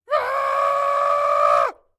latest / assets / minecraft / sounds / mob / goat / scream5.ogg
scream5.ogg